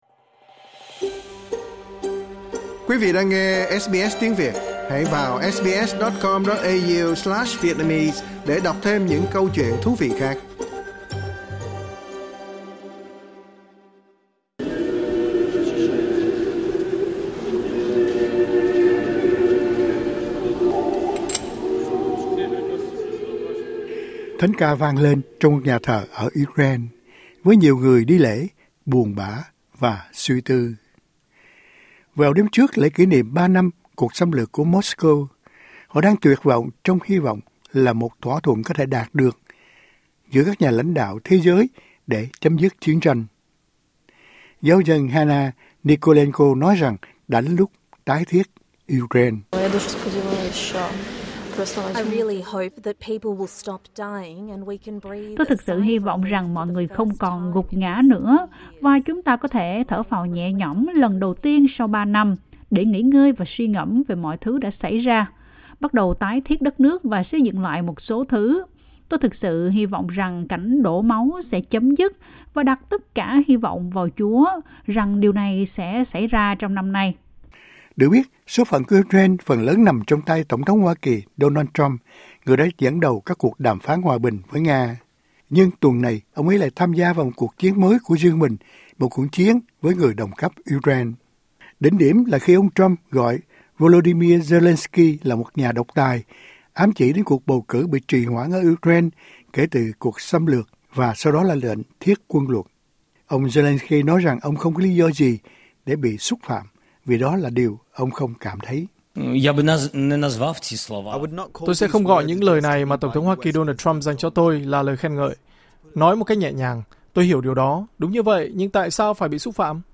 Thánh ca vang lên trong một nhà thờ Ukraine, với những người đi lễ buồn bã và suy tư.